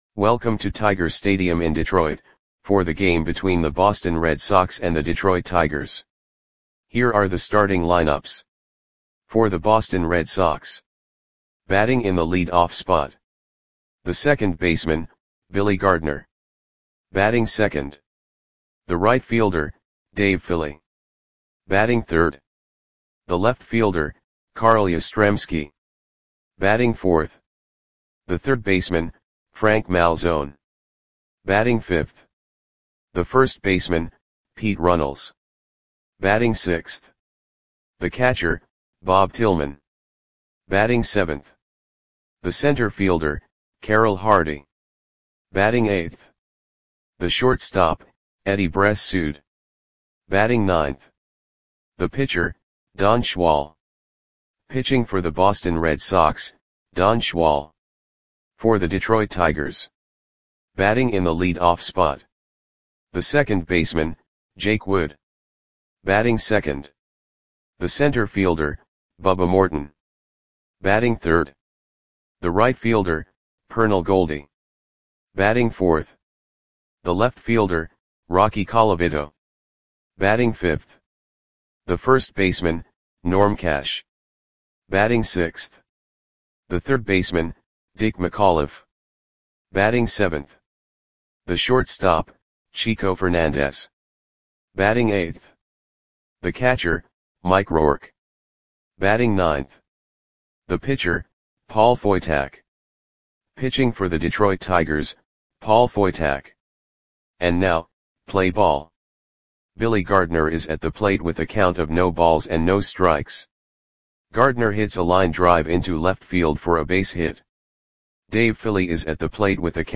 Audio Play-by-Play for Detroit Tigers on June 17, 1962
Click the button below to listen to the audio play-by-play.